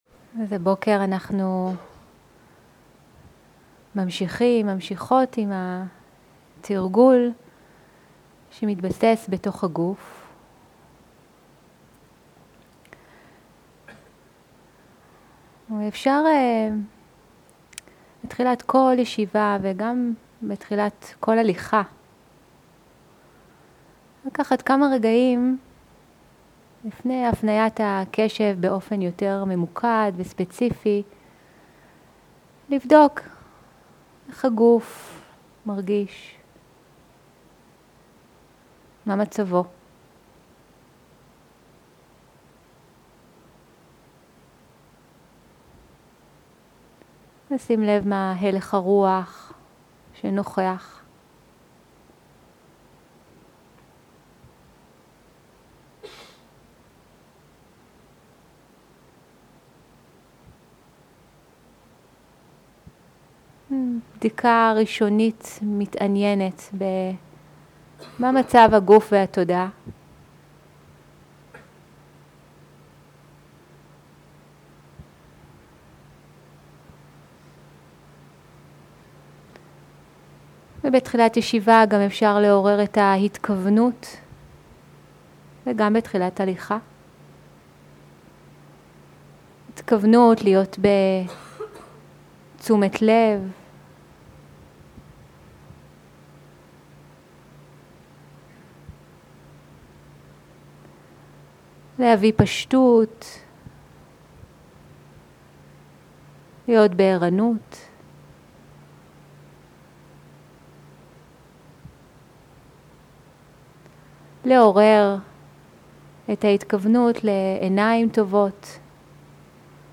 שיחת הנחיות למדיטציה שפת ההקלטה: עברית איכות ההקלטה: איכות גבוהה מידע נוסף אודות ההקלטה